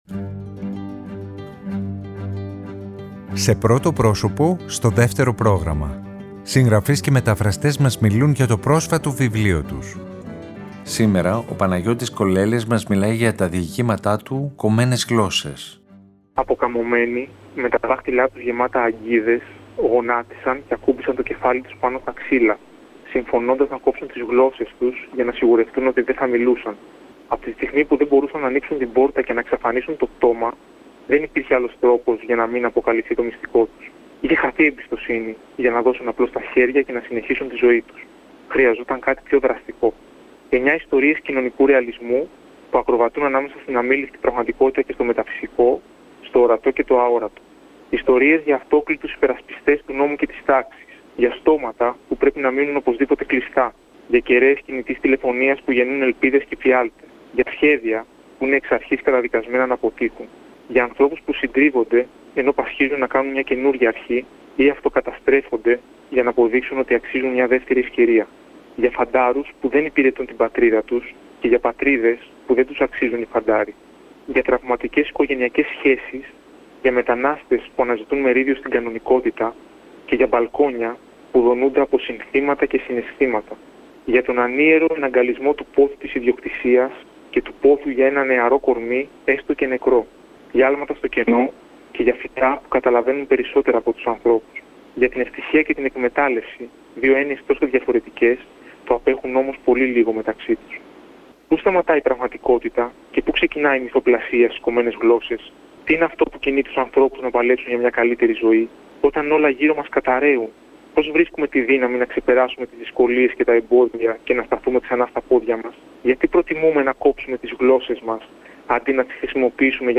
Συγγραφείς και μεταφραστές μιλάνε